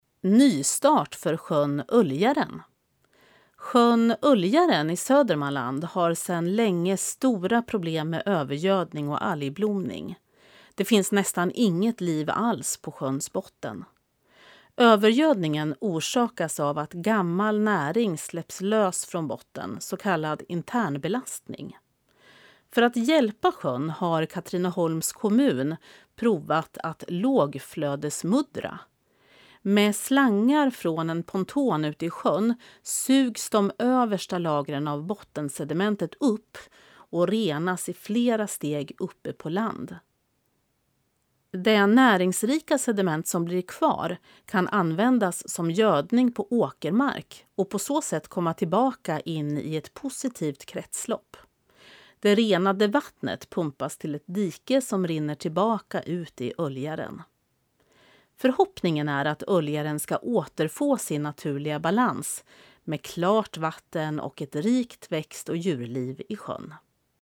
Klicka här för att lyssna till texten, inläst av professionell uppläsare